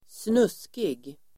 Uttal: [²sn'us:kig]